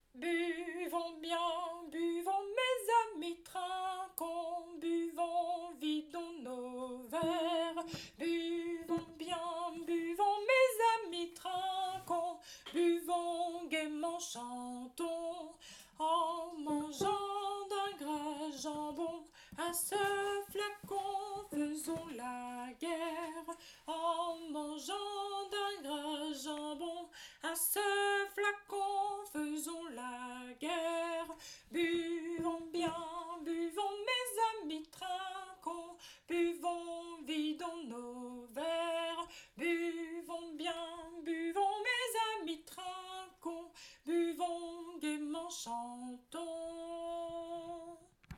> Enregistrement des voix séparées
basse
afd82-tourdion-basse.mp3